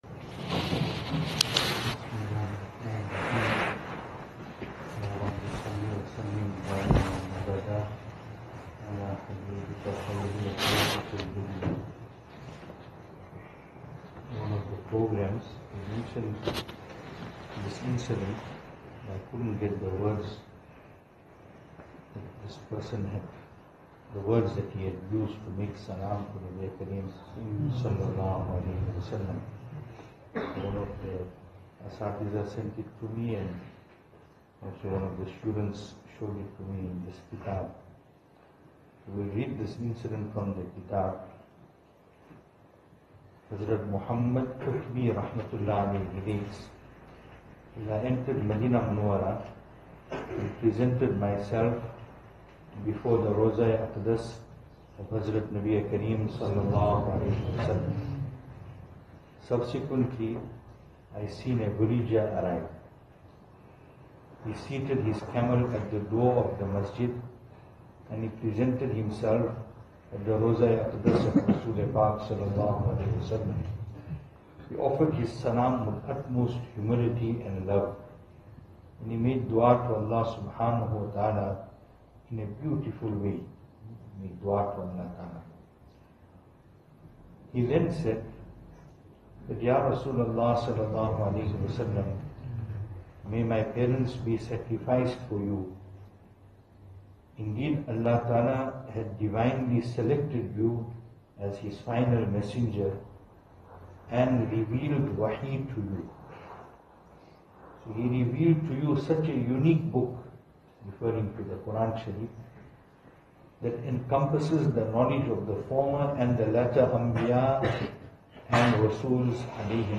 Venue: Albert Falls , Madressa Isha'atul Haq
Service Type: Islahi Majlis